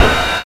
TRA05RIDE.wav